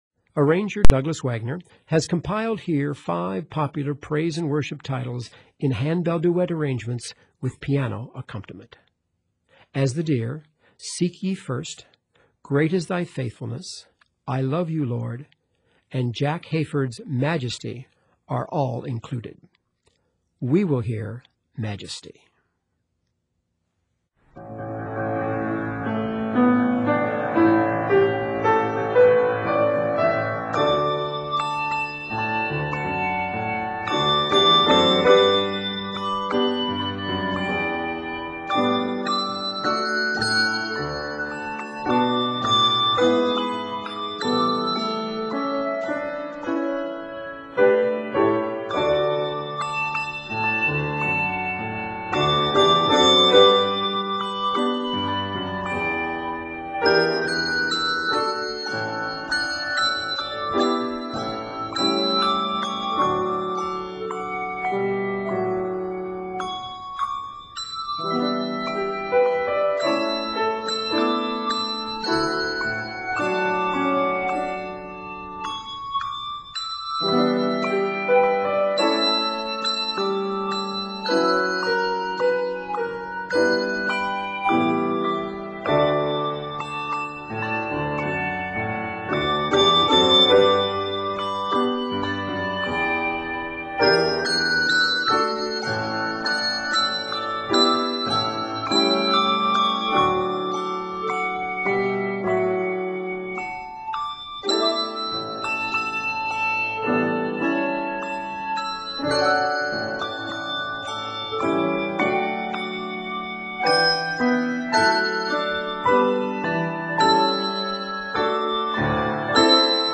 praise and worship